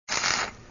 weld3.wav